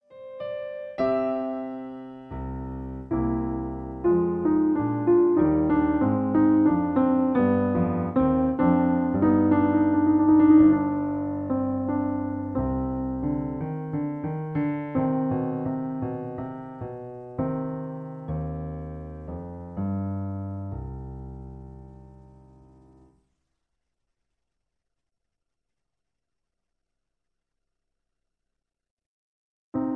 In C. Piano Accompaniment